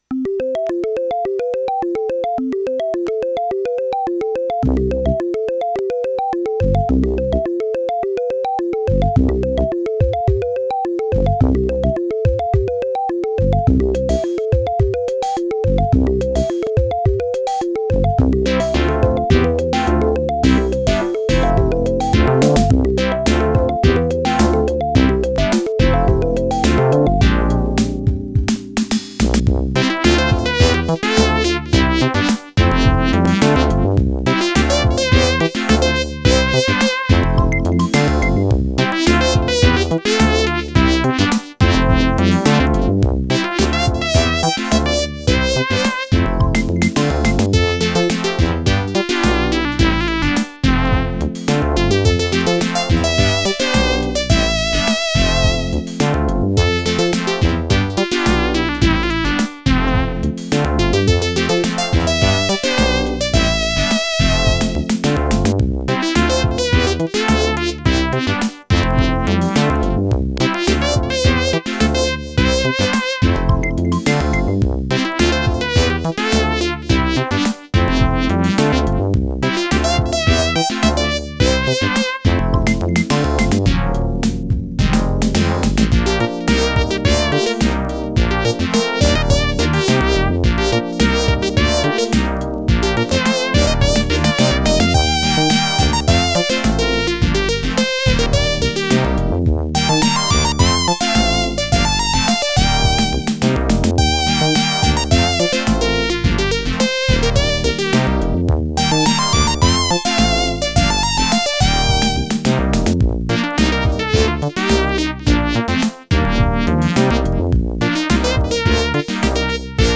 Creative SoundBlaster 16 ct2740
* Some records contain clicks.